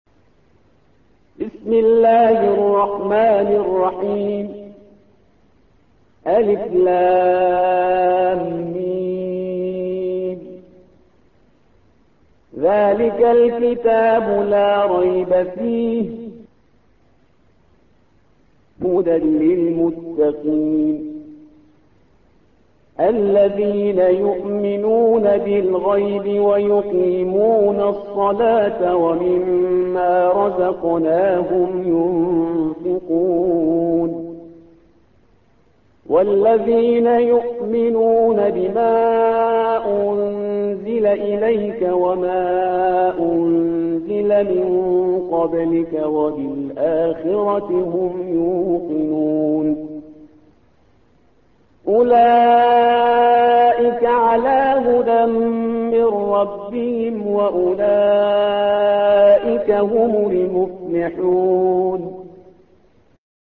الصفحة رقم 2 / القارئ